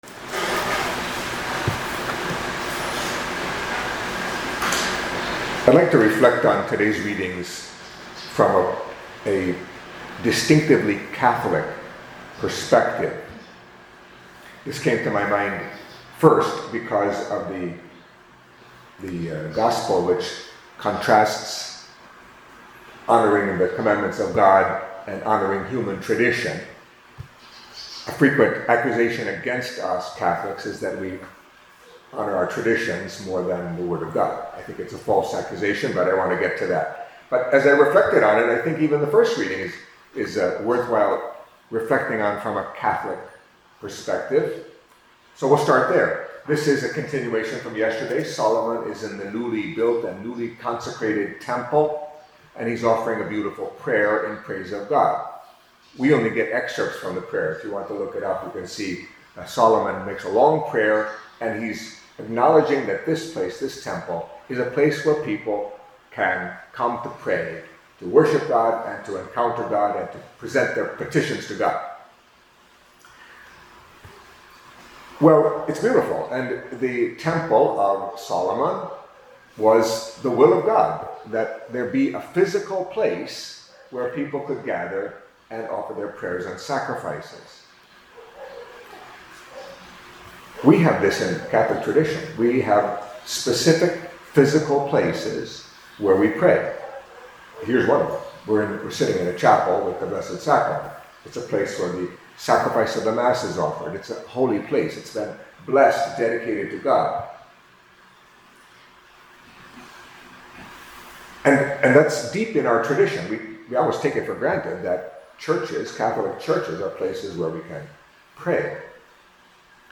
Catholic Mass homily for Tuesday of the Fifth Week in Ordinary Time